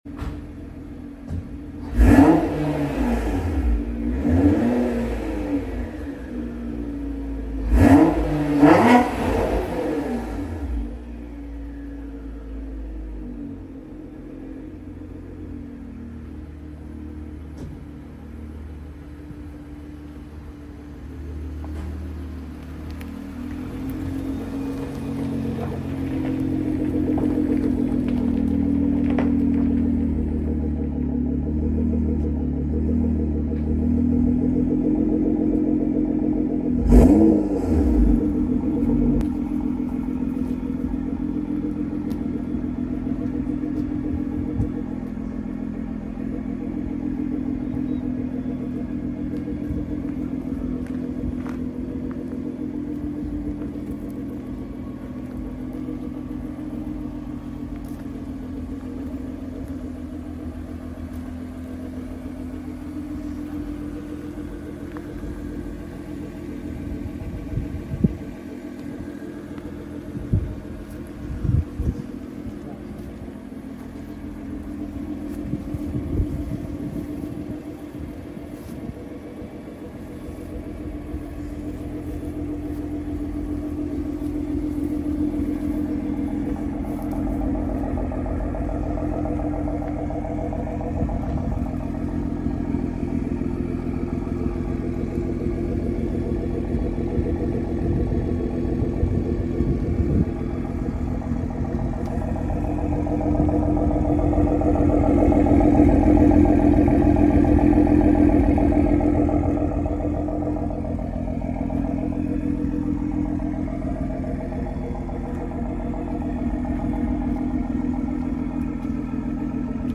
Fire Free + Pops and Bangs Flames
Feuer Frei Flammen und Pops and Bangs